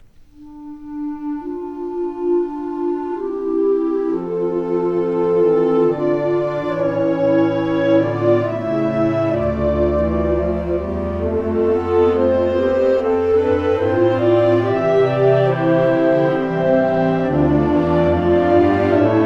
Categorie Harmonie/Fanfare/Brass-orkest
Subcategorie Hedendaagse blaasmuziek (1945-heden)
Bezetting Ha (harmonieorkest)
-Glass Harmonica (Musical Glasses) in E-flat and B-flat